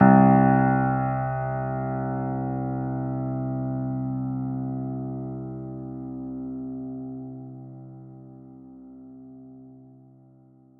piano-sounds-dev
Vintage_Upright
c1.mp3